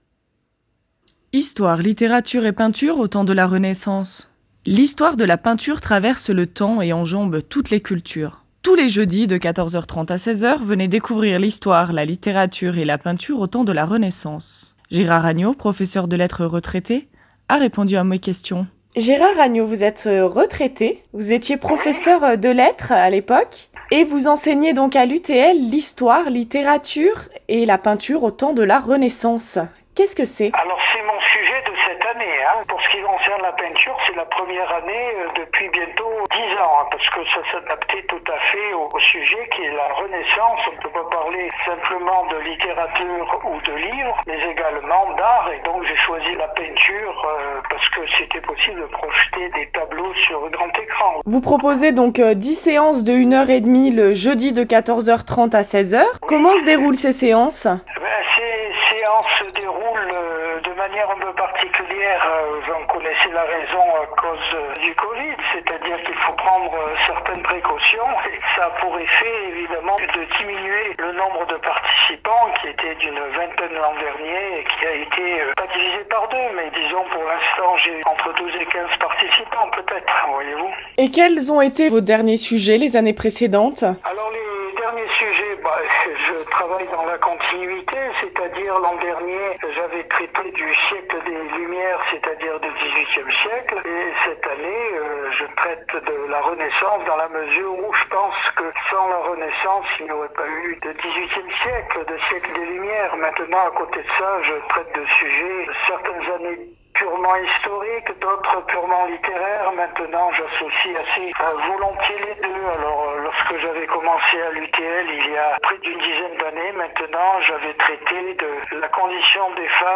professeur de lettres retraité répond aux questions